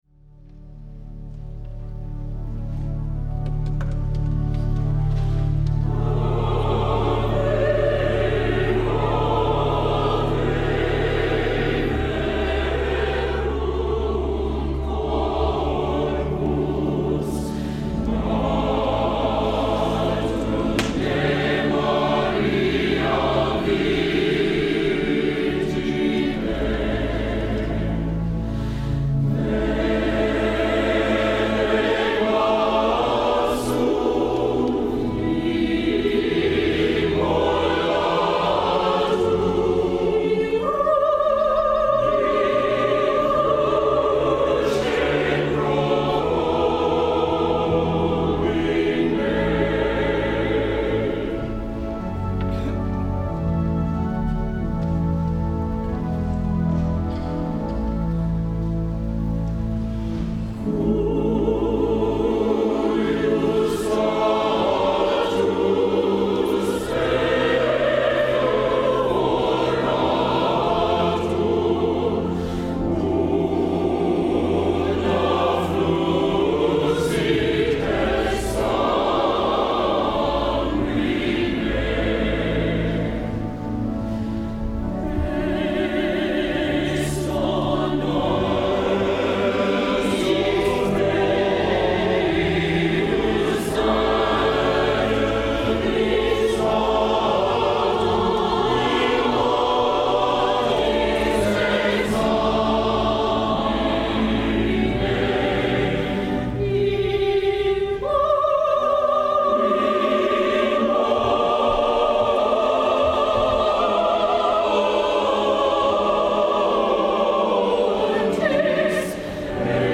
live recordings of various clients
Choir